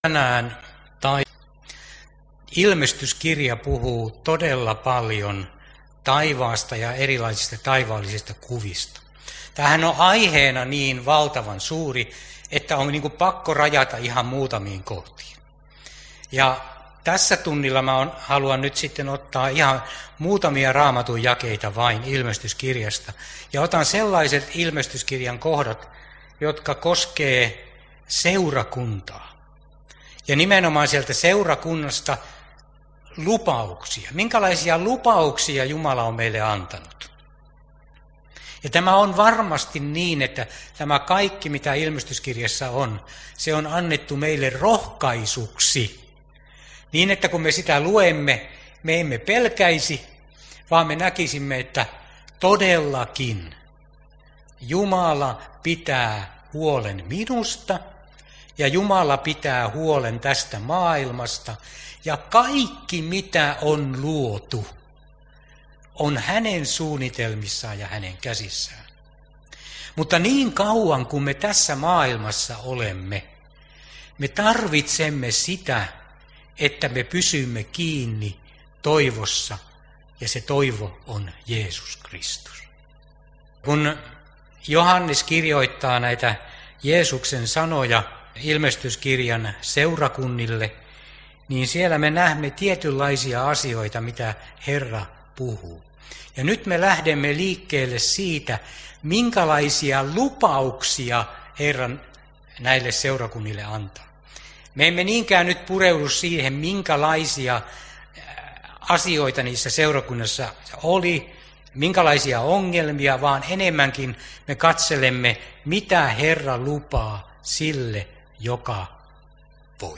Kankaanpää